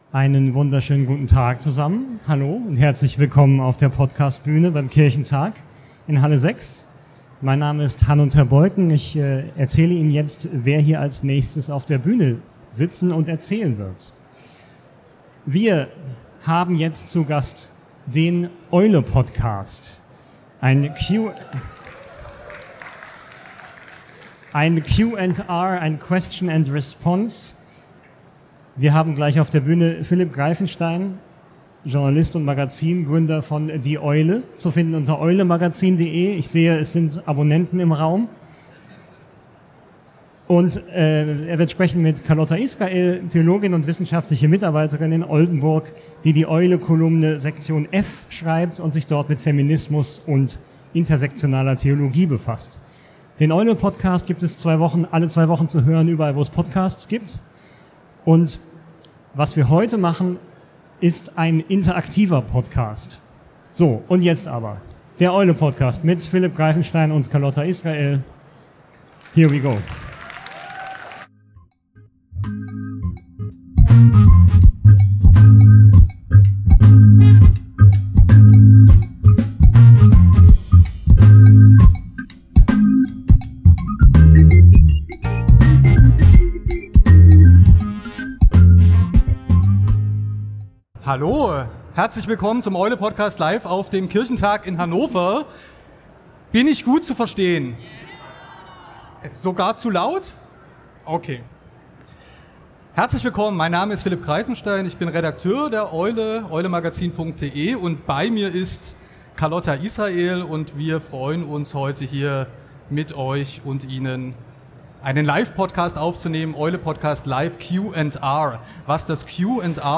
„Eule-Podcast Q & R“ live vom Kirchentag 2025